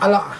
[ala] noun branch